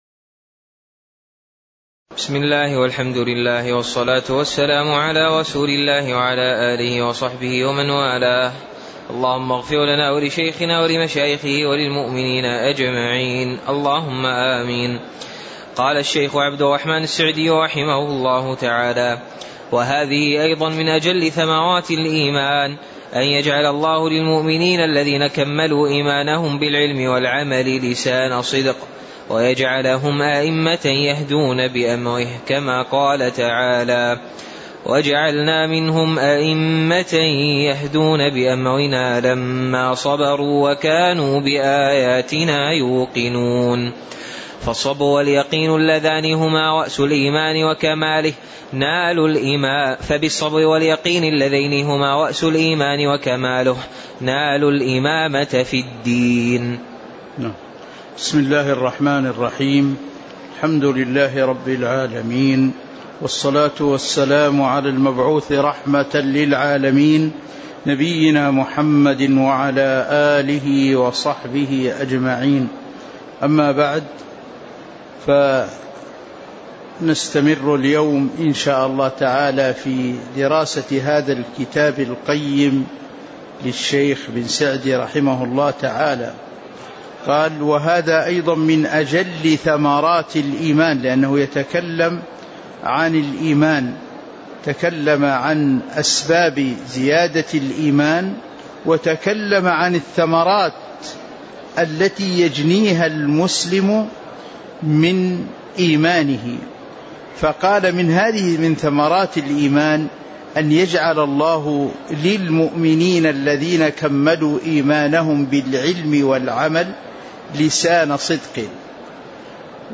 تاريخ النشر ١٢ جمادى الأولى ١٤٤٥ هـ المكان: المسجد النبوي الشيخ